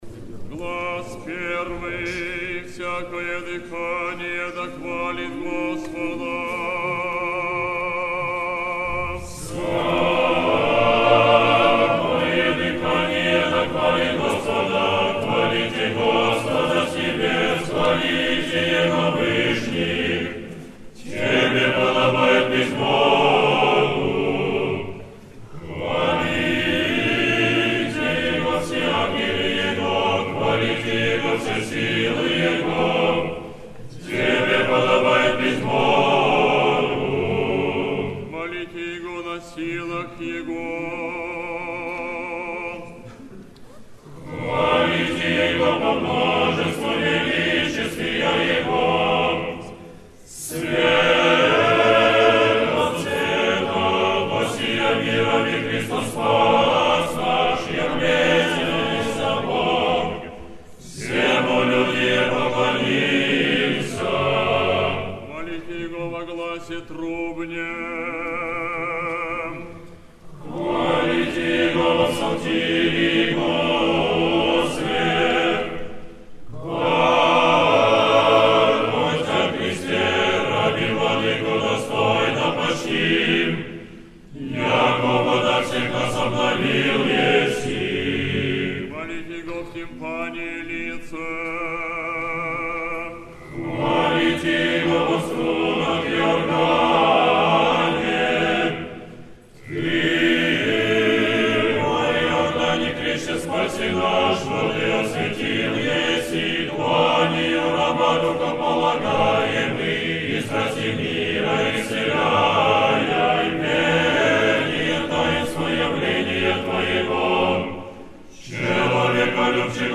стихиры на хвалитех
Hor-Moskovskogo-Sretenskogo-monastyrya-Svyatoe-Bogoyavlenie-Stihiry-na-hvalitehmuzofon.com_.mp3